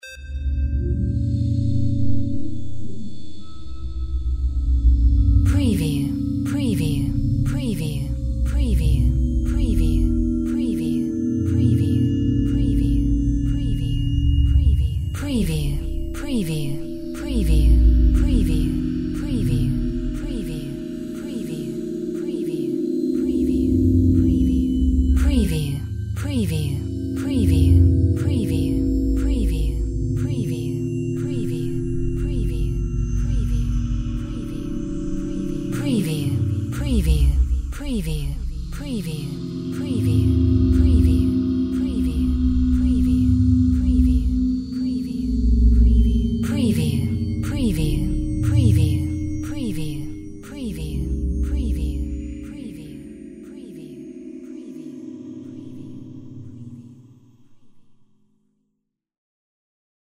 Drone Riveroftones No Drip FX 01
Morphing and evolving tones. With a hint of data transmission.
Stereo sound effect - Wav.16 bit/44.1 KHz and Mp3 128 Kbps
previewDRONE_RIVEROFTONES_NODRIP_FX_WBSD01.mp3